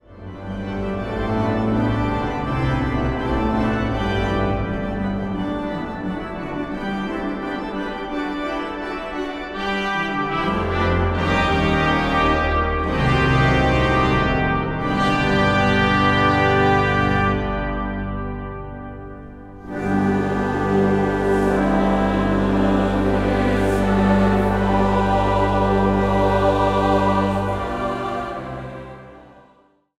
trompet
orgel.
Zang | Gemengd koor